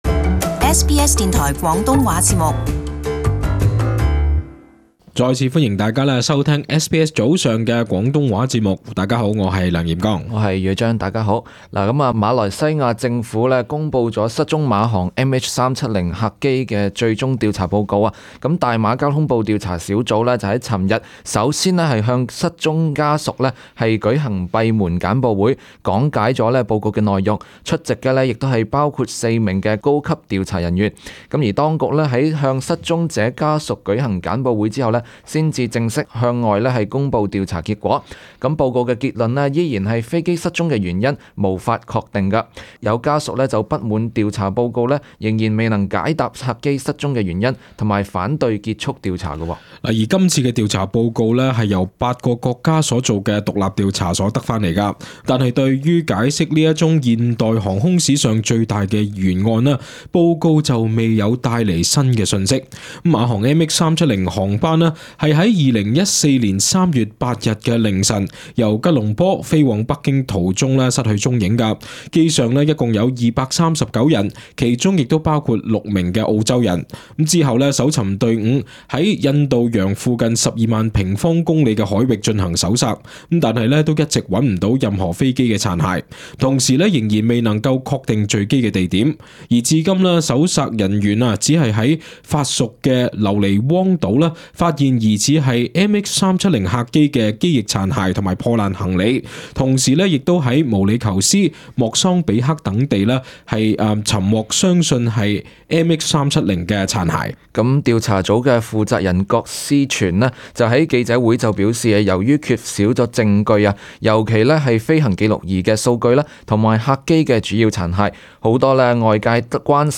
【時事報導】馬航MH370客機最終報告：「原因不明」